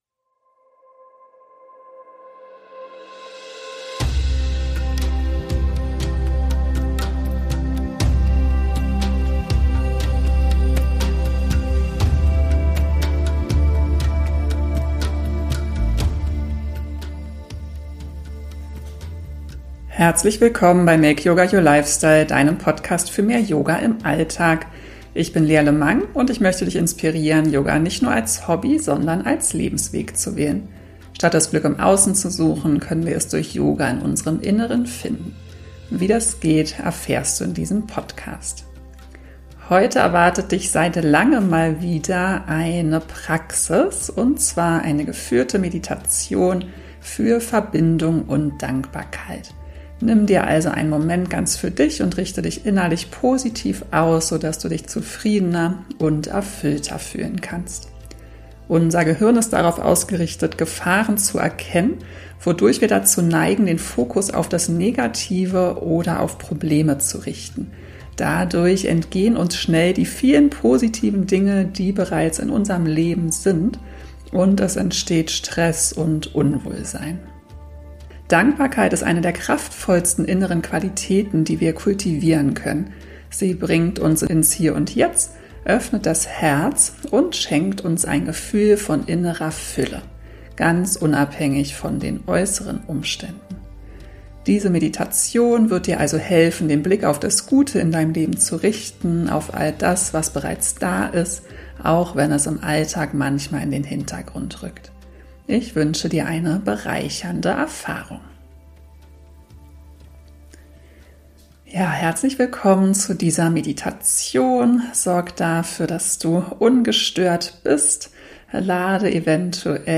In dieser Folge erwartet dich eine geführte Meditation für Verbindung und Dankbarkeit. Nimm dir diesen Moment ganz für dich und richte dich innerlich positiv aus, so dass du dich zufriedener und erfüllter fühlst.